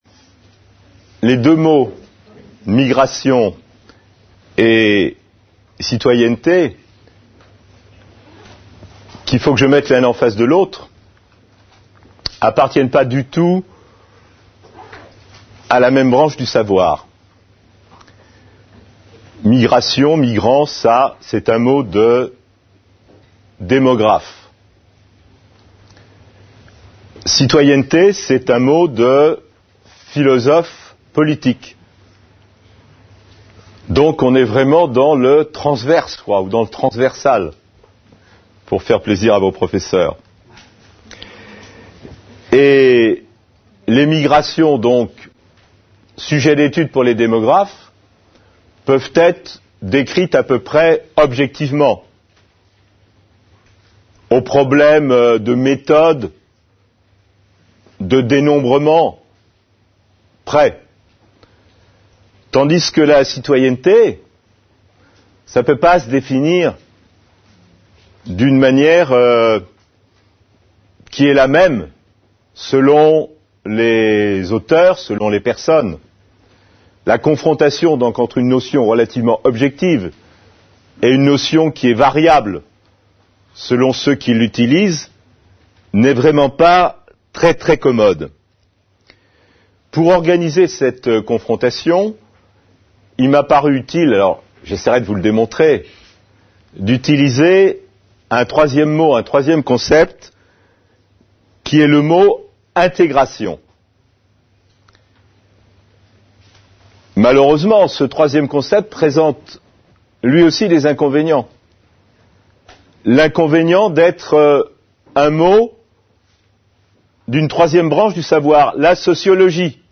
Tout dépend de son intégration, c’est ce que démontre Daniel Malingre, Conseiller-maître à la Cour des comptes. Une conférence de l'UTLS au Lycée avec Daniel Malingre. Lycée Henri Parriat (Montceau Les Mines, 71)